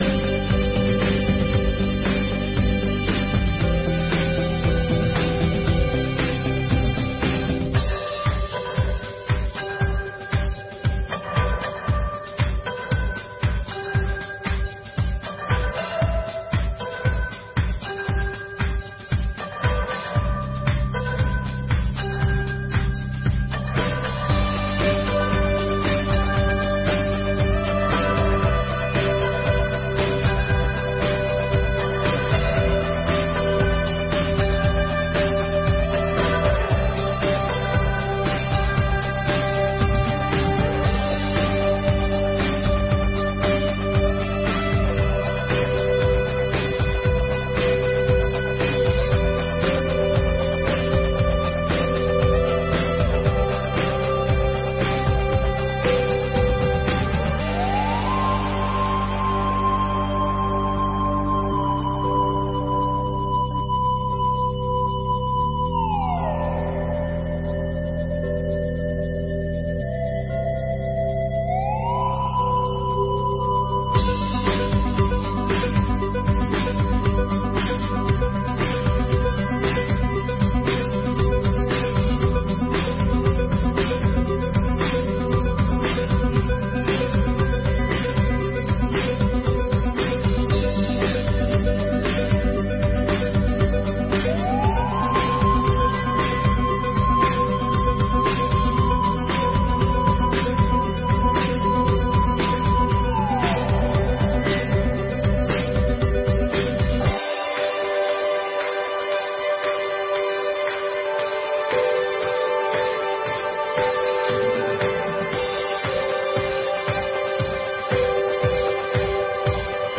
Audiência Pública: discussão do Projeto de Lei nº 56/2022